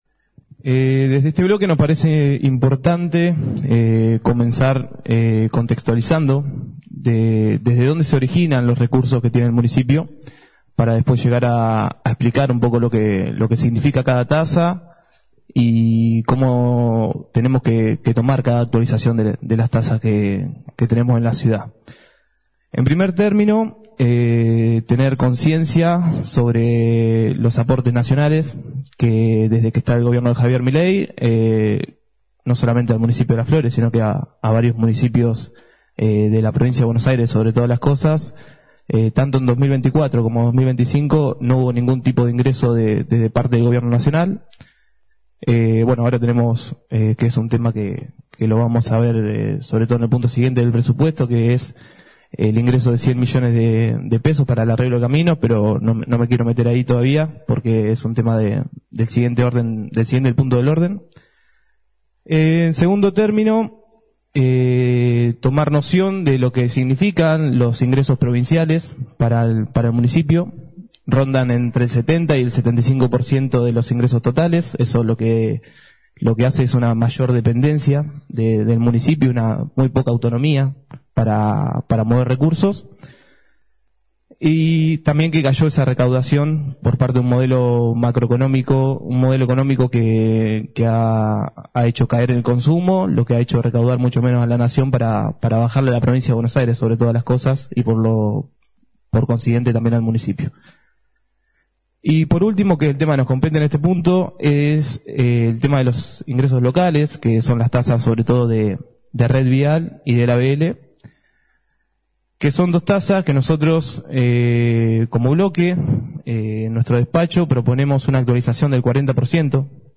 Audio Concejal Román Caputo (Bloque Todos por Las Flores)